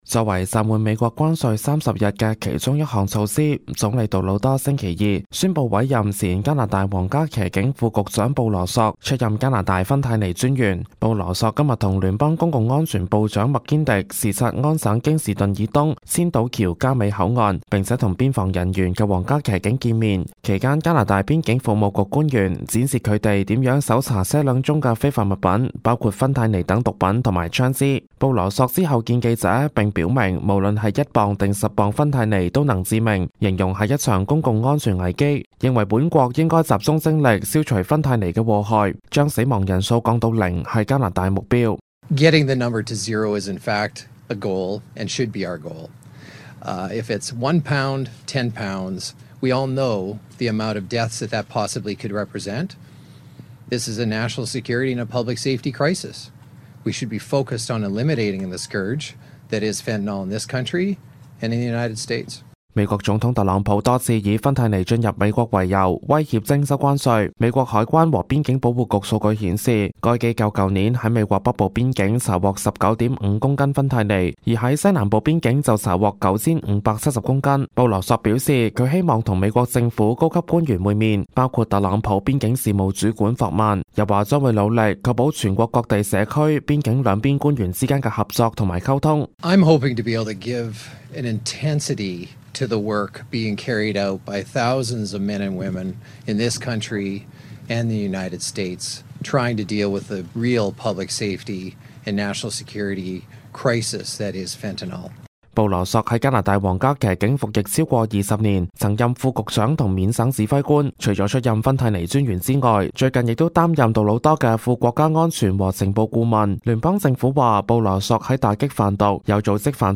news_clip_22464.mp3